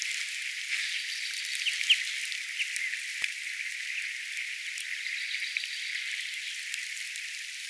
erikoisesti laulaa peippo taustalla pari säettä,
peipon lentoääniä
erikoisesti_laulaa_ilm_peippo_taustalla_kaksi_saetta_peipon_lentoaania_edustalla.mp3